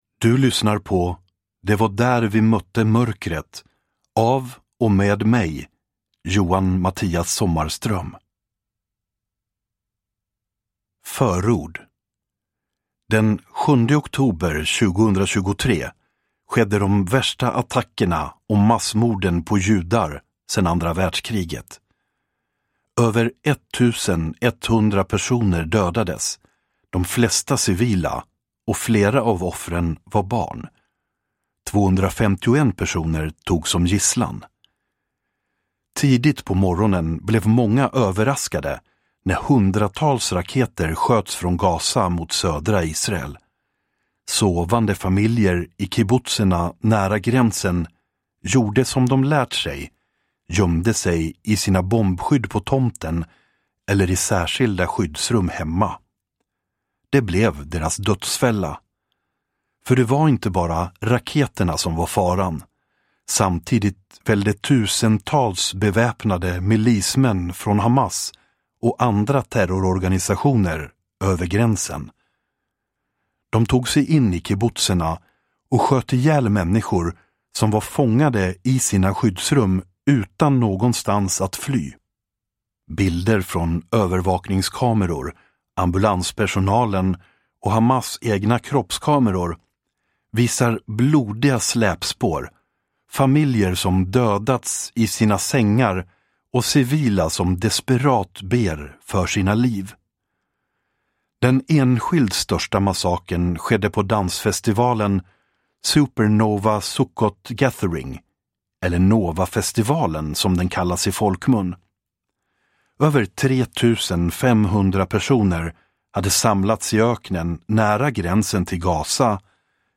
Det var där vi mötte mörkret – Ljudbok